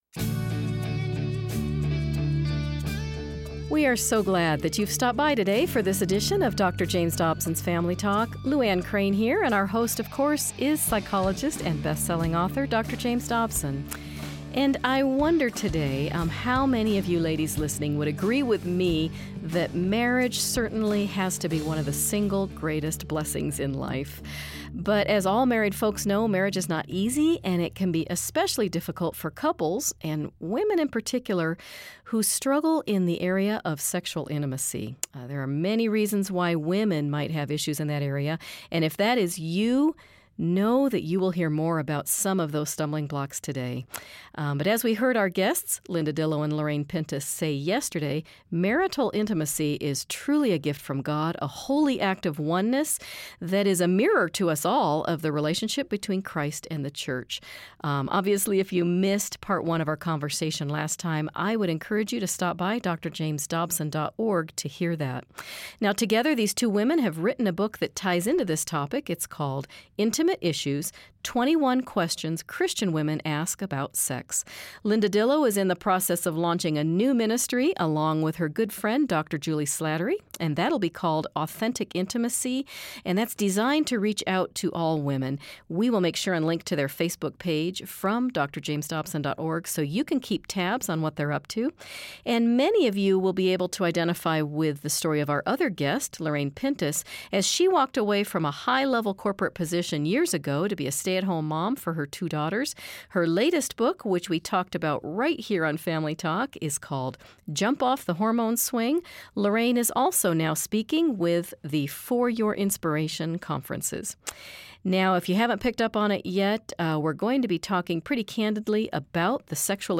But in a culture that denigrates and exploits this sacred connection, it's easy for women to bring a lot of baggage into marriage. Dr. Dobson speaks with his guests about how to find freedom from your past, and society's definition of intimacy.